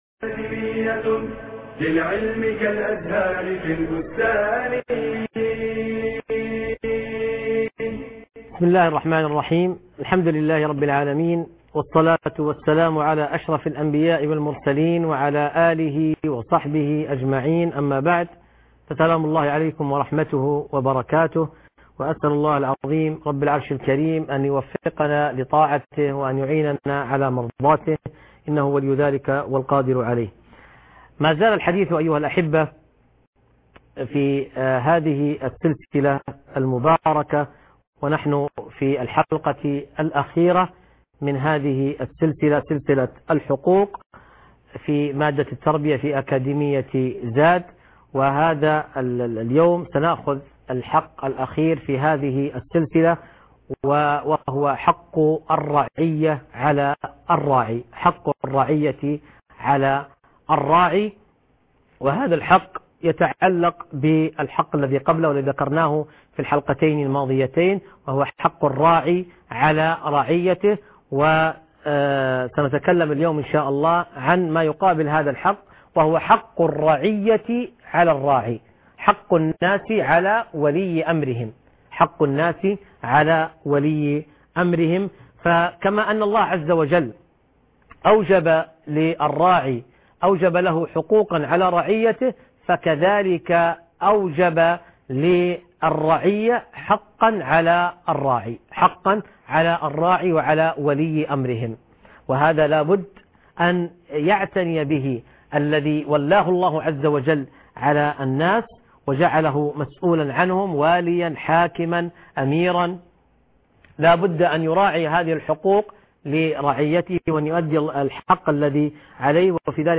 المحاضرة الرابعة و العشرون -حق الراعى على الرعية